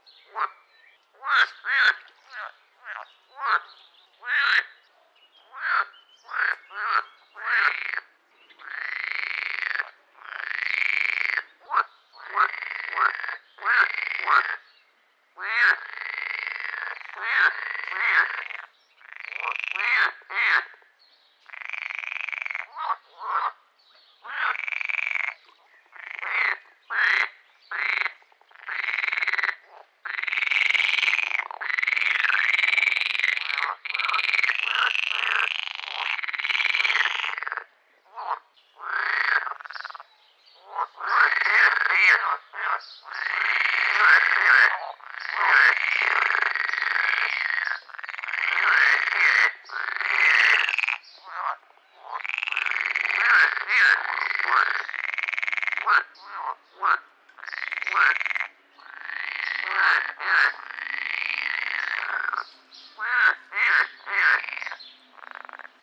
Audiodateien, nicht aus dem Schutzgebiet
Teichfrosch UB
teichfrosch_Konzert.wav